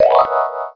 power_picked.wav